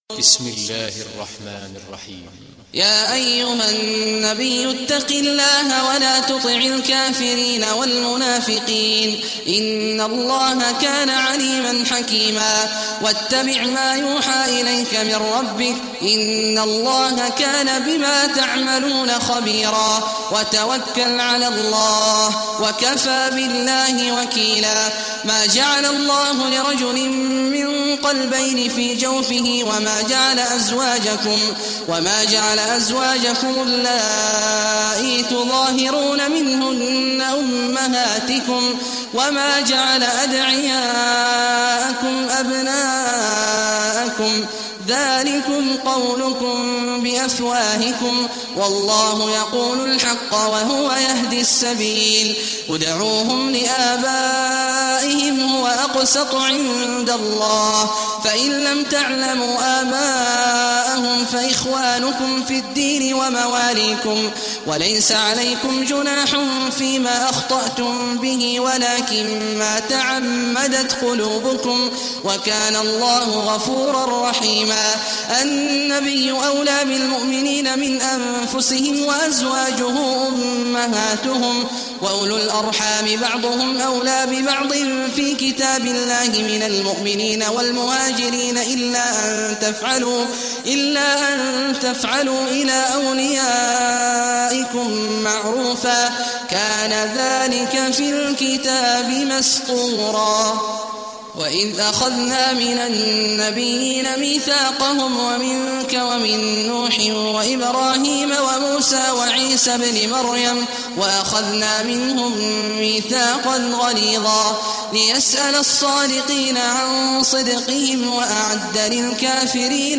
Surah Al-Ahzab Recitation by Sheikh Awad Juhany
Surah Al-Ahzab, listen or play online mp3 tilawat / recitation in Arabic in the beautiful voice of Sheikh Abdullah Awad al Juhany.